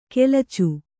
♪ kelacu